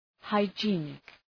{,haıdʒı’enık} (Επίθετο) ● υγιεινός